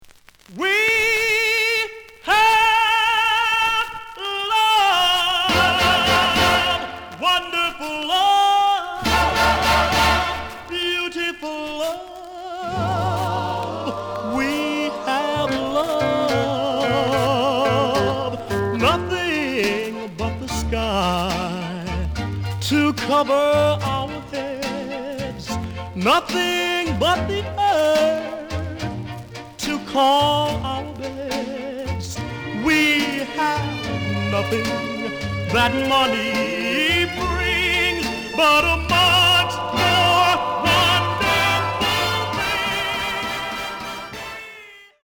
The audio sample is recorded from the actual item.
●Genre: Rhythm And Blues / Rock 'n' Roll
Some noise on beginning of both sides.)